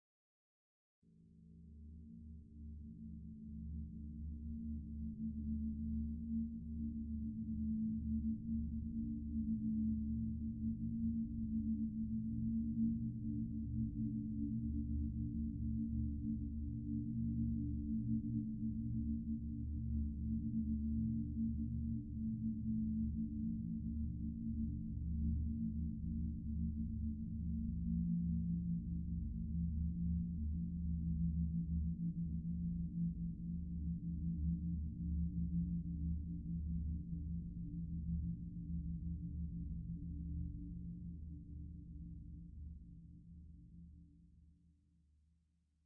无人机 " drone7
描述：用vst仪器制作
Tag: 未来 无人机 驱动器 背景 隆隆声 黑暗 冲动 效果 FX 急诊室 悬停 发动机 飞船 氛围 完善的设计 未来 空间 科幻 电子 音景 环境 噪音 能源 飞船 大气